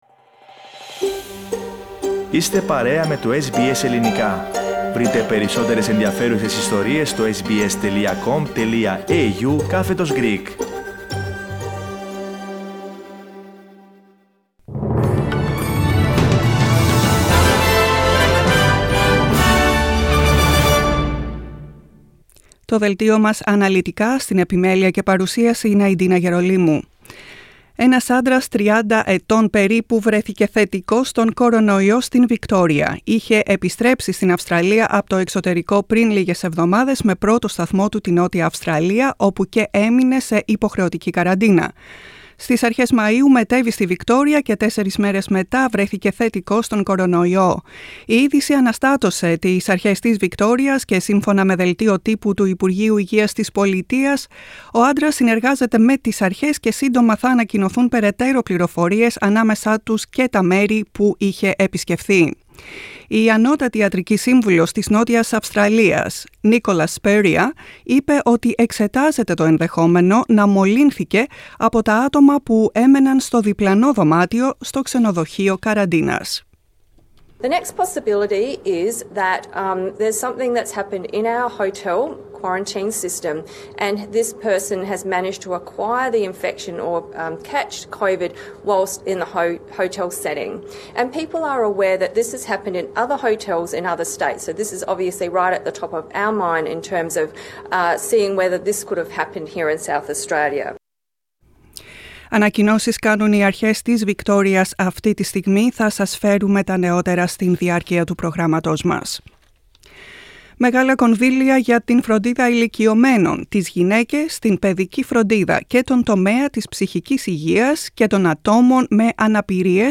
Το κεντρικό δελτίο ειδήσεων του Ελληνικού Προγράμματος.